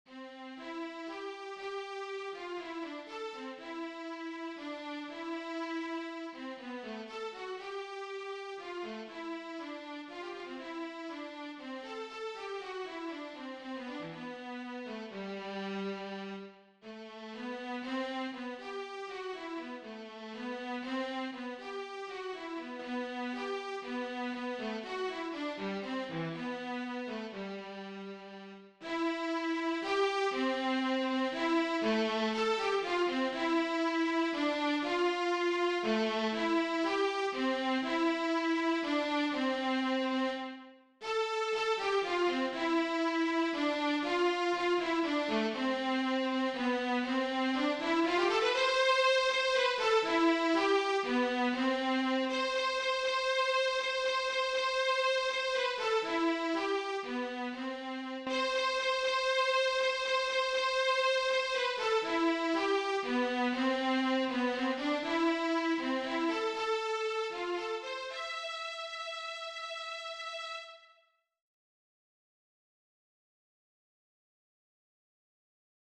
DIGITAL SHEET MUSIC - VIOLA SOLO
Romantic Period